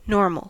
Ääntäminen
IPA : /ˈnɔː(ɹ)məl/ US : IPA : /ˈnɔɹməl/